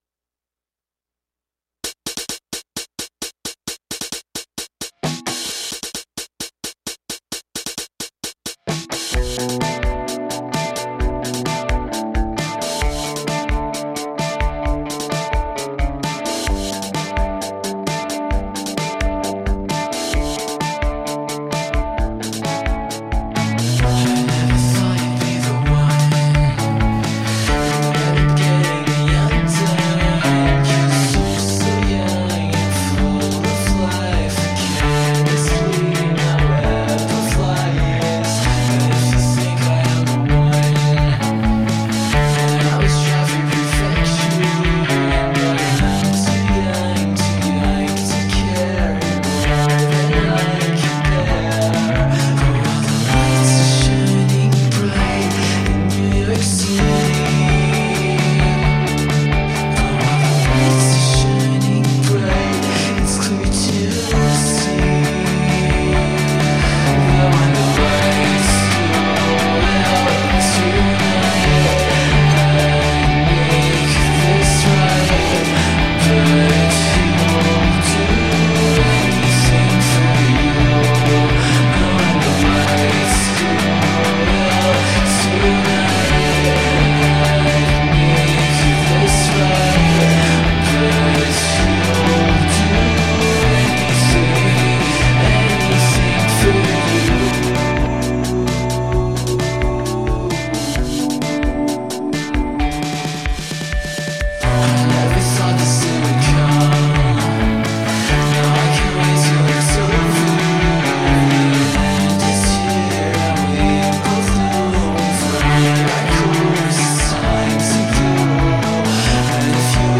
alternative rock band